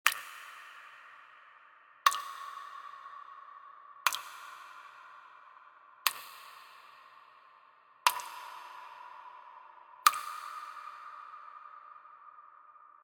Water Drips Echo
water-drips-echo-3.ogg